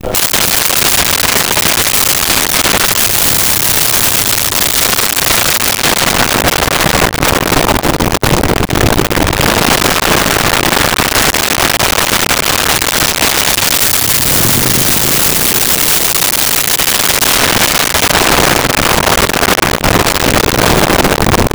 Motorcycle Start Away By
Motorcycle Start Away By.wav